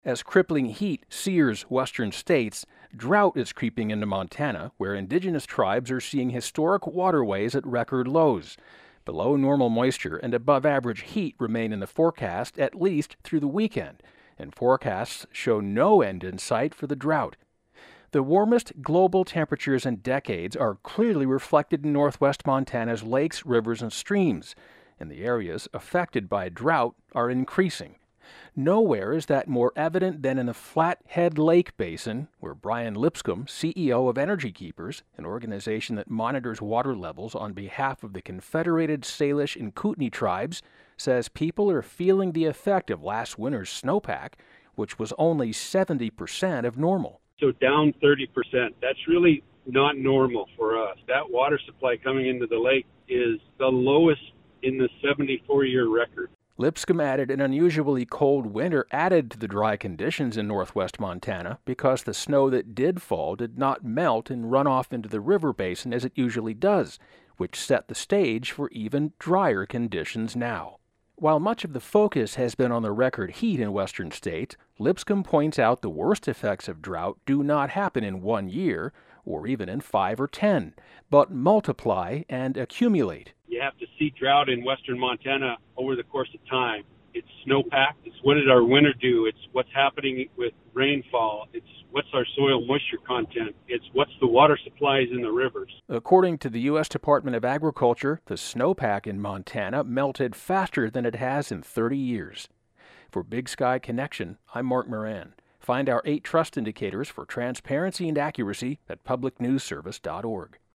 Inc. (Salish SAY-lish) Kootenai (KOOT-nee)